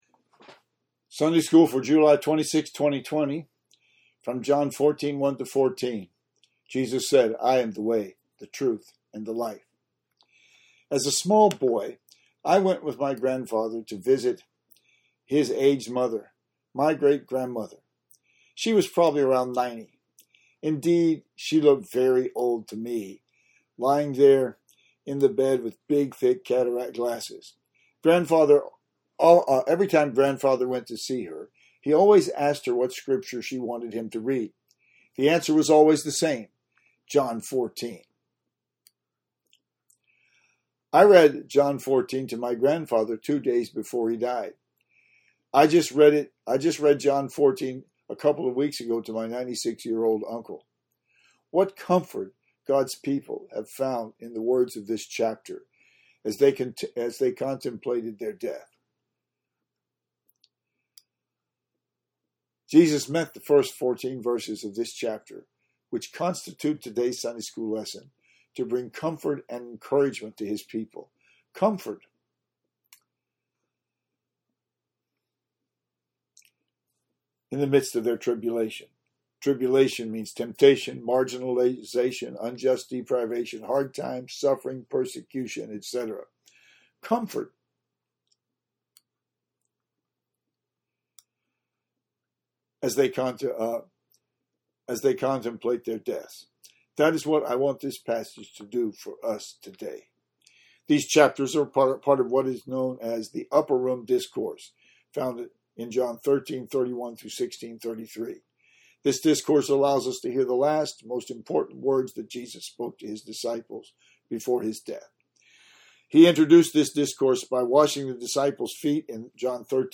For an explanation and application of John 14:1-14, today’s Sunday school lesson, click below: